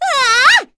Pansirone-Vox_Attack2.wav